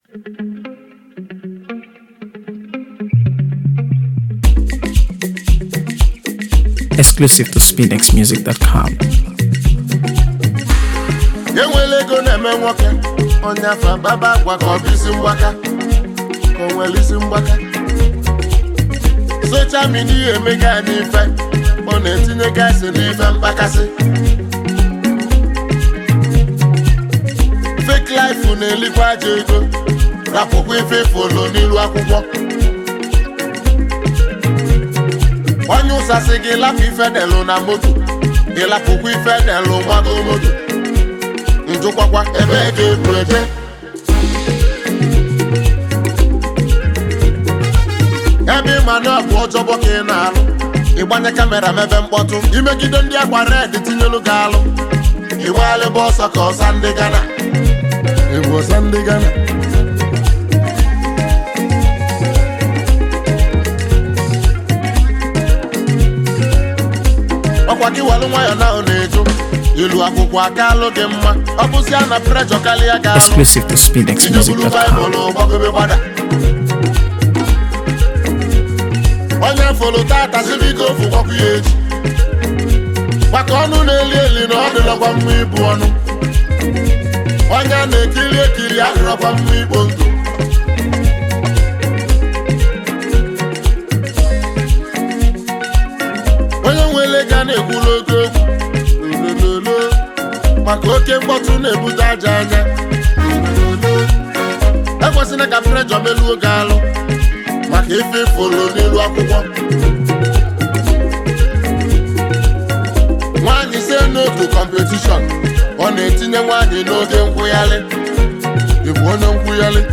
AfroBeats | AfroBeats songs
traditional highlife style